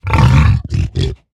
1.21.4 / assets / minecraft / sounds / mob / hoglin / angry2.ogg
angry2.ogg